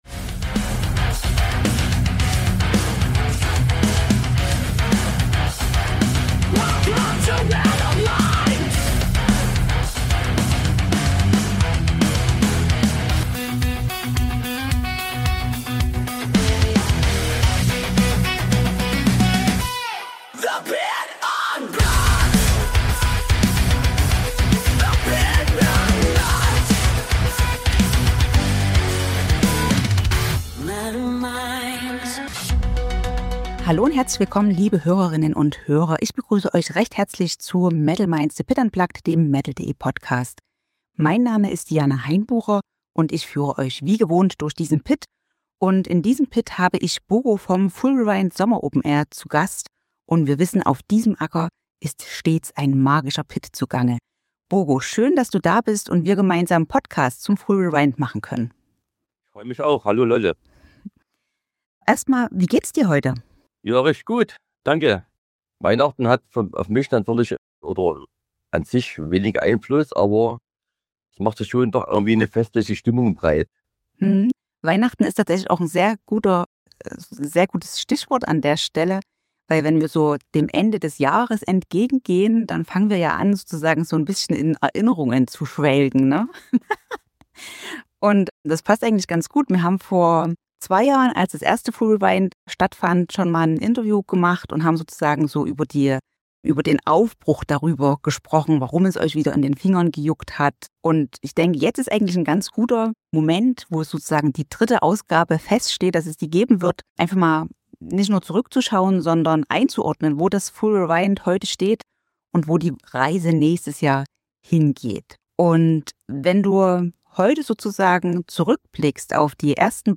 Im Gespräch geht es um klare Haltungen im Booking, die Bedeutung der Knüppelnacht, den bewussten Verzicht auf überladenes Rahmenprogramm und die Frage, wie Festivals heute glaubwürdig bleiben können, in einer Zeit, in der wirtschaftlicher Druck viele Veranstaltungen in Richtung Mainstream treibt. Ein reflektiertes Gespräch über Festivalmachen als Herzenssache, über Gemeinschaft statt Hochglanz und darüber, wofür FULL REWIND stehen will und wofür ganz bewusst nicht.